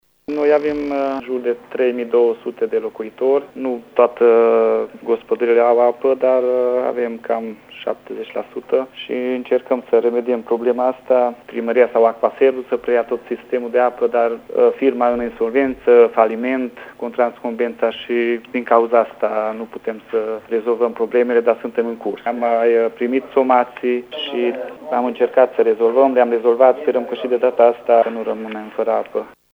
Viceprimarul Orban Sandor: